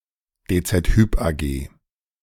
The DZ Bank Group includes: Bausparkasse Schwäbisch Hall, a building society; DZ HYP (German pronunciation: [deː t͡sɛt ˈhyp aːˌɡeː]
De-DZ_Hyp_AG.ogg.mp3